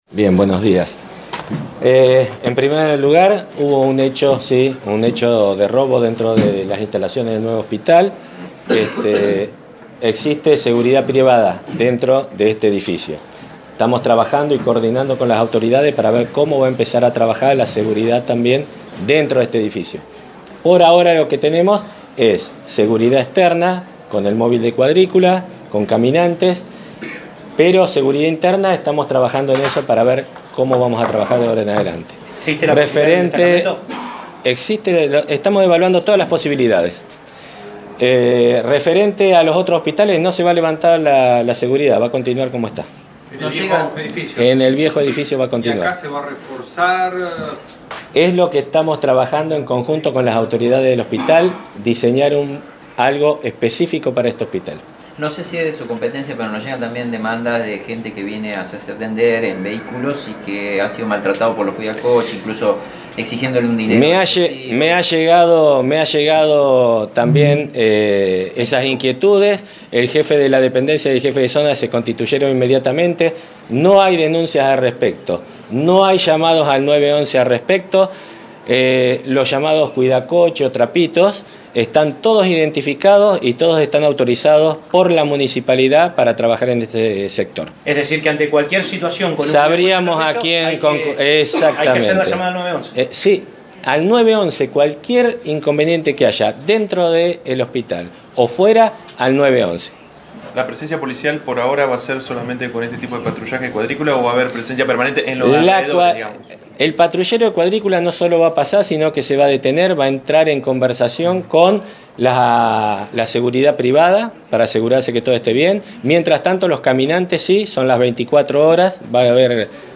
Finalmente, el Jefe de la URI, Julián Alegre abordó la situación de seguridad del nuevo edificio del Hospital Iturraspe y respondió a las consultas por supuestos aprietes de trapitos o cuidacoches. El jefe policial recordó que los mismos están habilitados por el municipio y ante cualquier problema deben denunciar al 911.